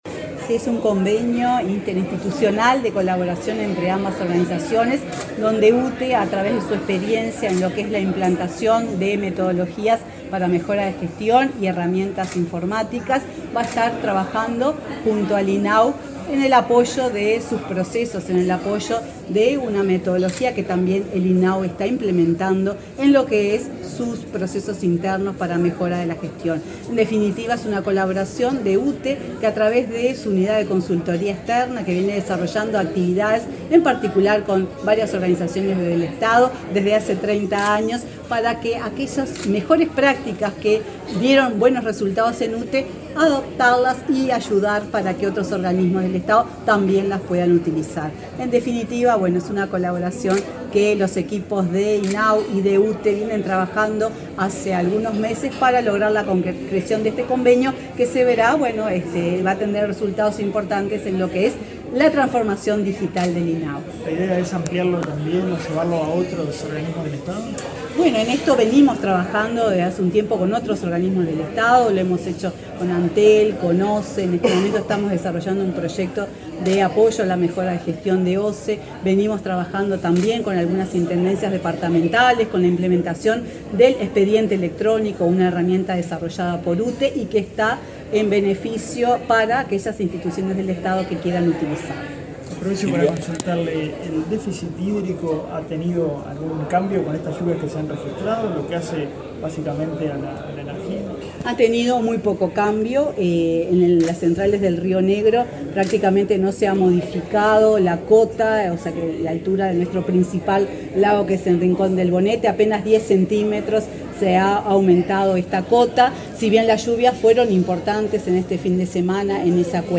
Declaraciones de la presidenta de UTE, Silvia Emaldi
Luego dialogó con la prensa.